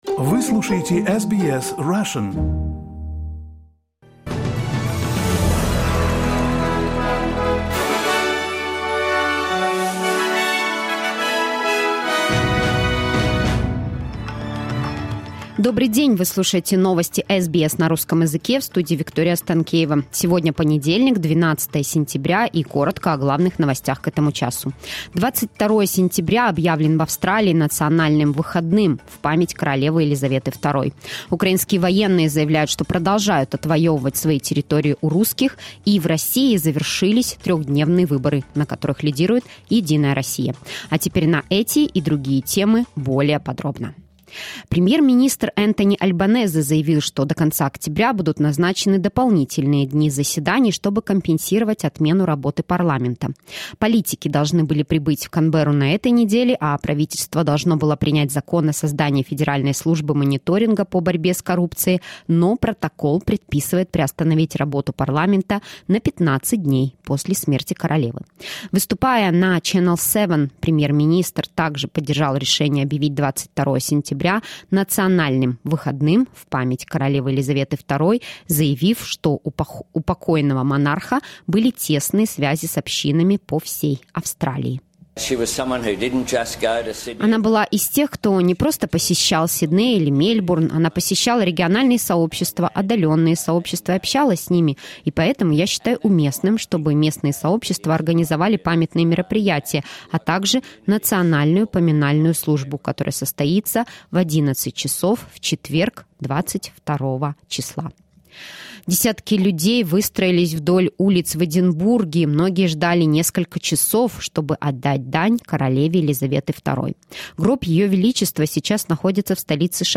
SBS news in Russian - 12.09.2022